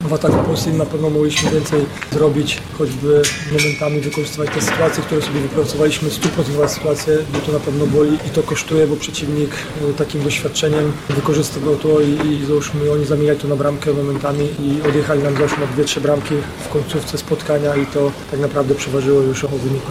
Puławianie w kolejnym meczu PGNiG Superligi pokonali tamtejszy MMTS 29:25. Mimo porażki zadowolony z postawy swoich podopiecznych był trener gospodarzy Bartłomiej Jaszka, który wskazał również, co zadecydowało o zwycięstwie Azotów: